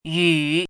chinese-voice - 汉字语音库
yu3.mp3